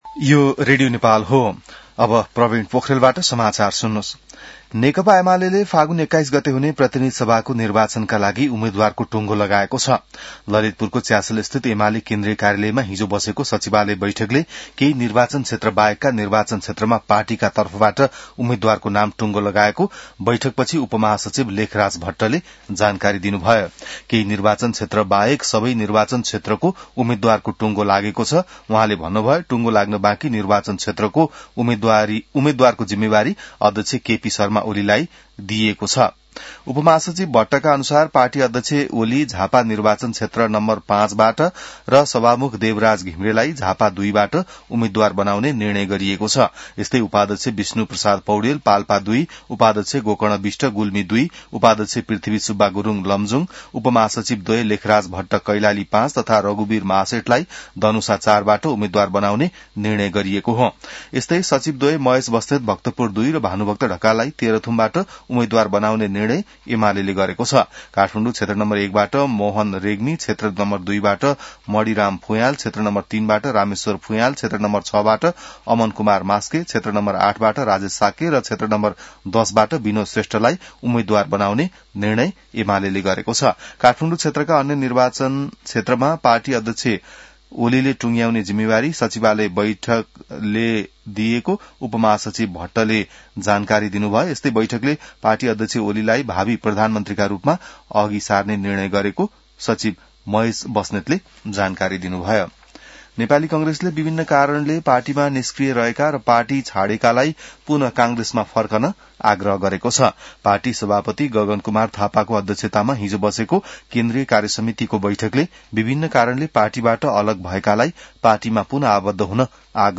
बिहान ६ बजेको नेपाली समाचार : ४ माघ , २०८२